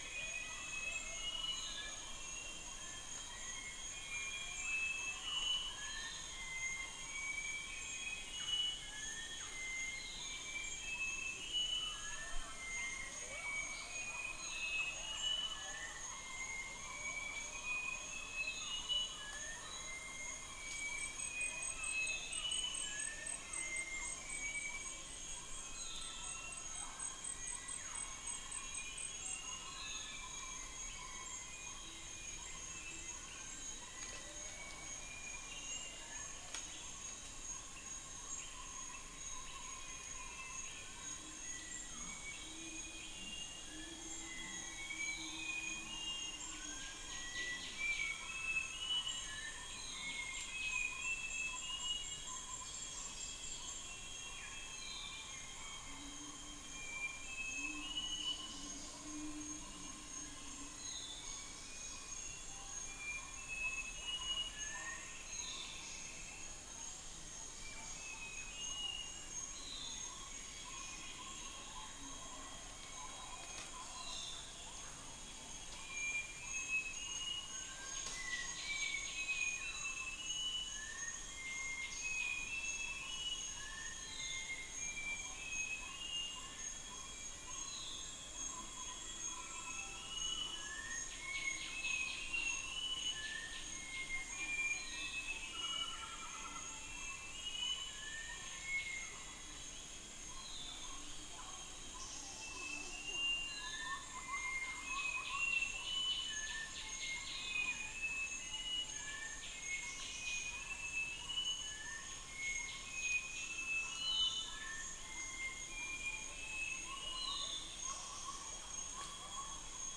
Macronus ptilosus
Buceros rhinoceros
unknown bird
Corvus enca
Gracula religiosa
Pelargopsis capensis